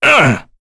Kain-Vox_Damage_02.wav